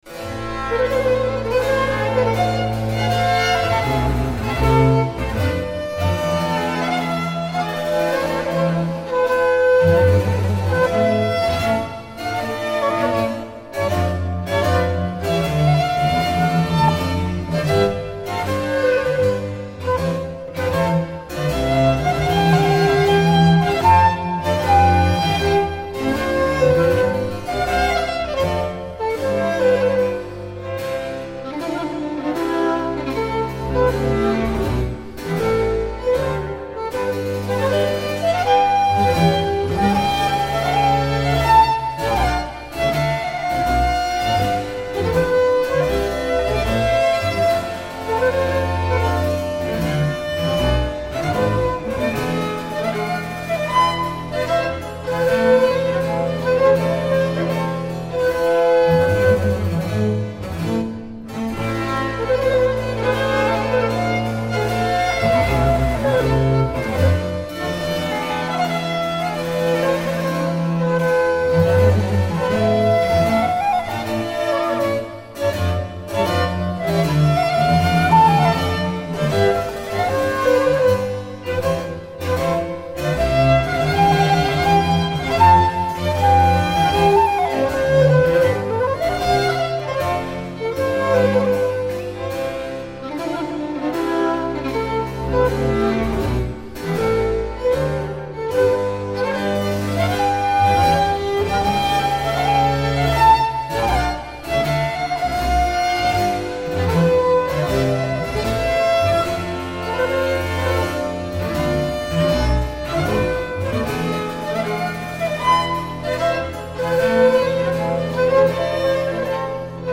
Incontro